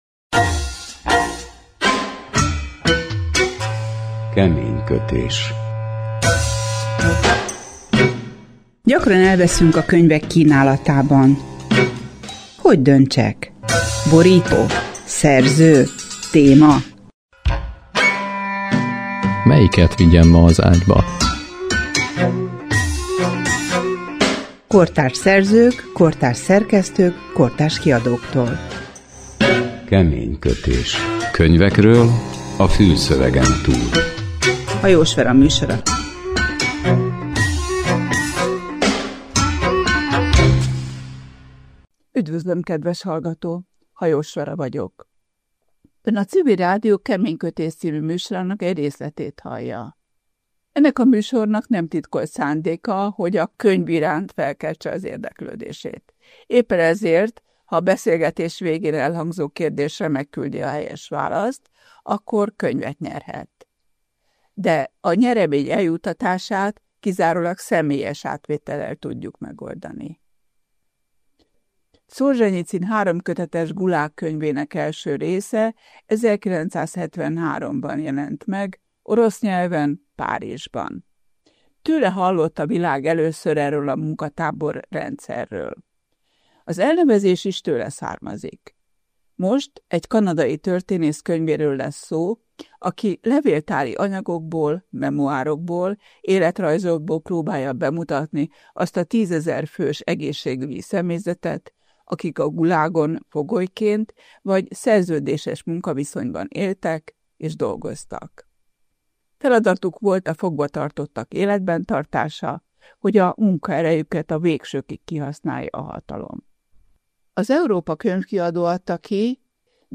Podcast beszélgetés a könyvről